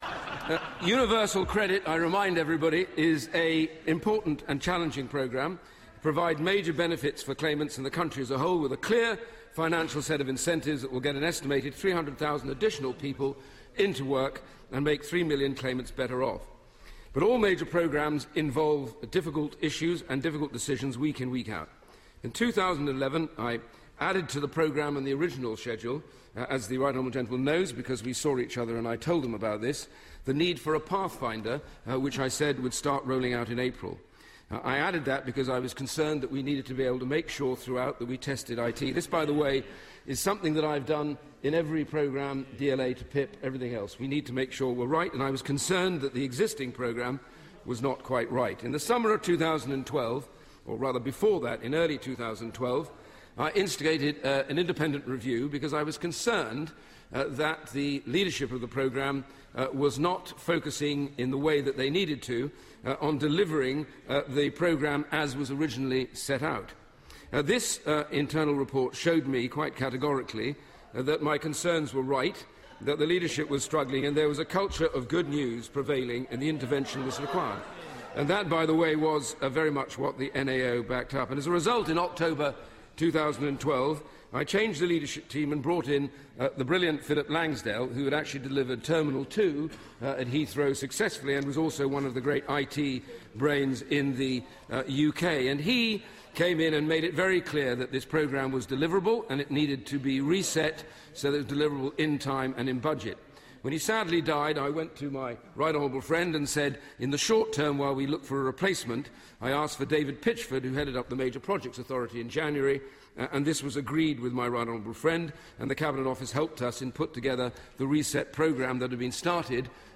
Iain Duncan Smith defends his welfare reforms in the Commons.